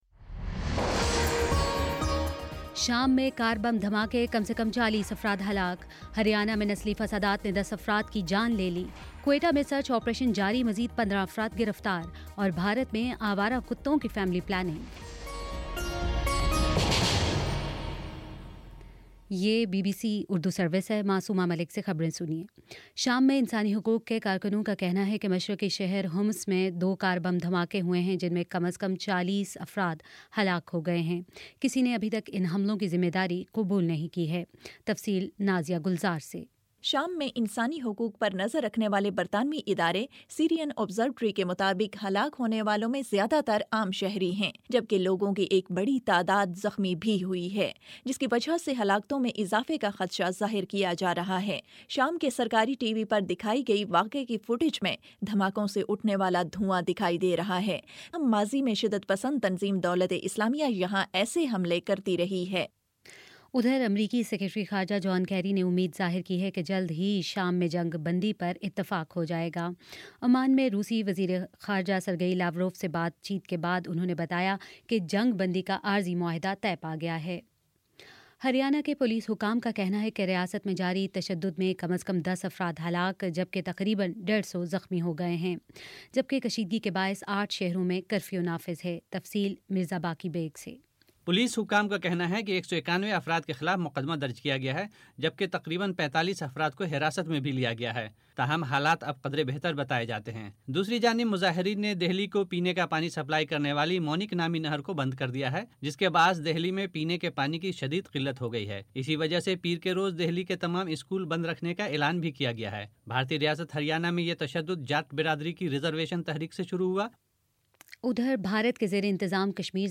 فروری 21 : شام چھ بجے کا نیوز بُلیٹن